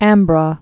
(ămbrô)